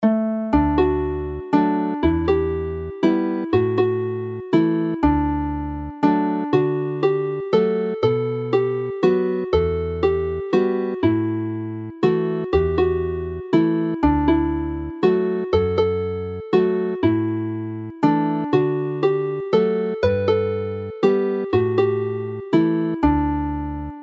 Chwarae'r alaw yn D
Play the melody in D